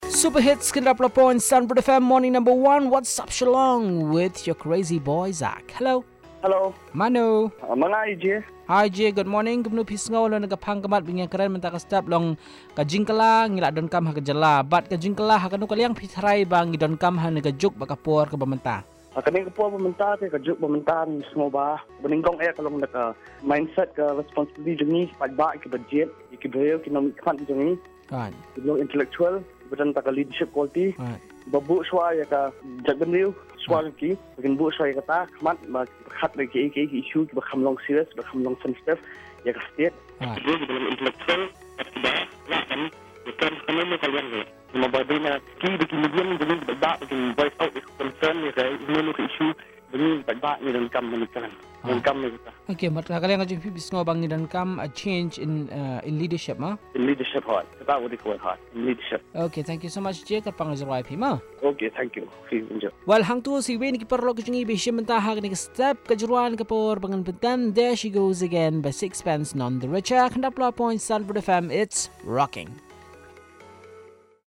Caller 3 on change